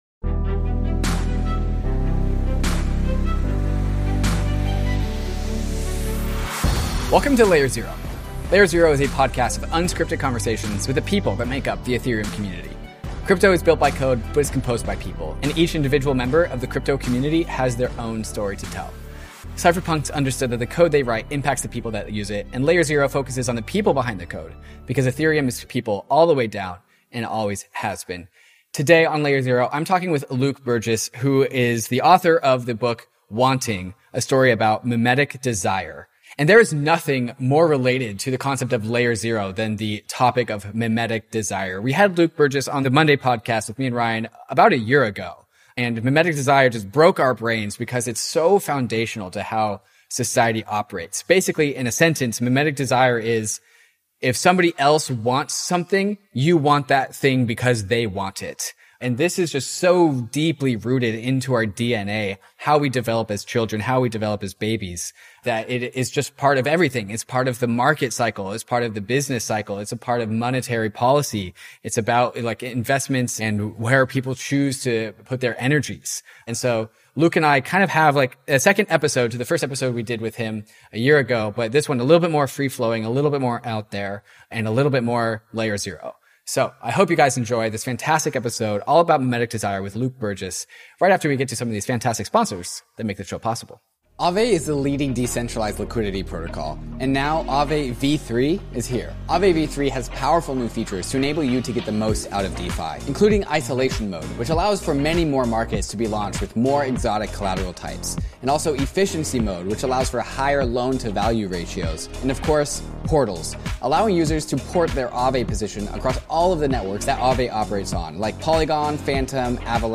Mimetic desire is a part of everything. After this interview, you’ll start to see it everywhere.